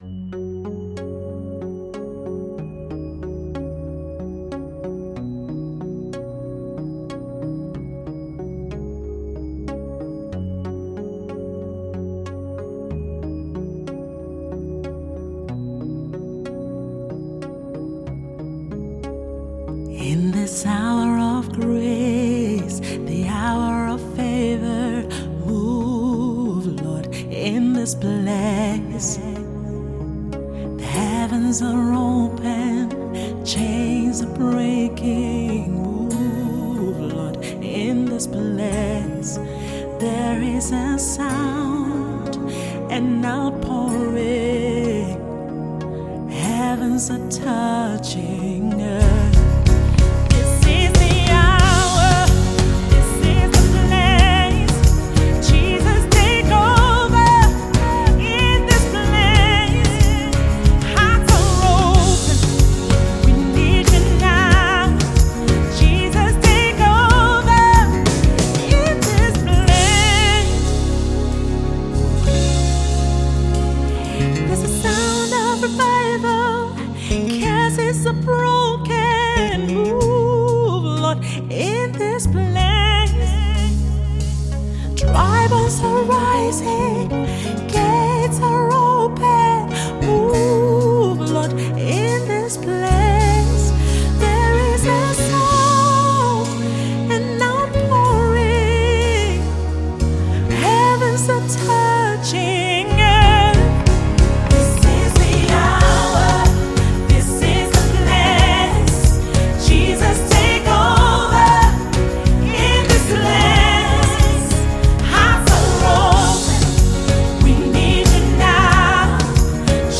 Worship Leader
has a contemporary sound